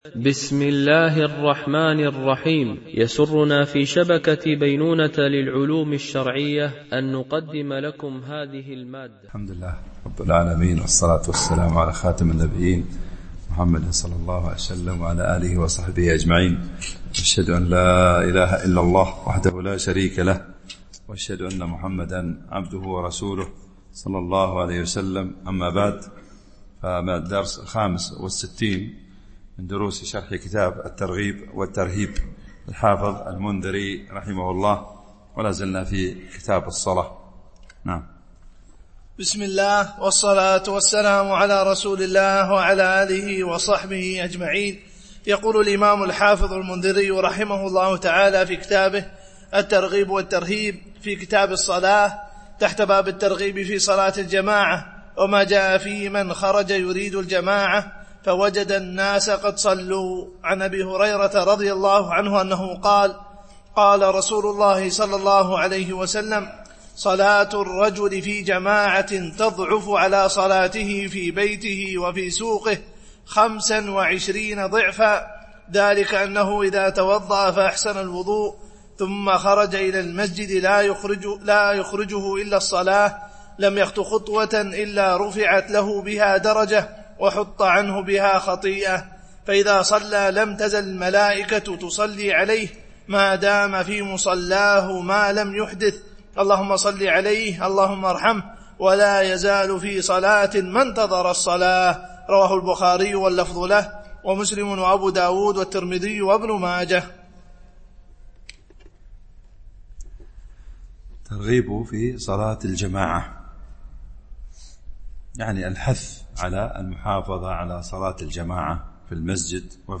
شرح كتاب الترغيب والترهيب - الدرس 64 ( كتاب الصلاة .الحديث 574 - 576)